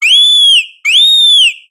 infinitefusion-e18/Audio/SE/Cries/ORICORIO_1.ogg at a50151c4af7b086115dea36392b4bdbb65a07231